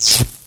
Sparks.wav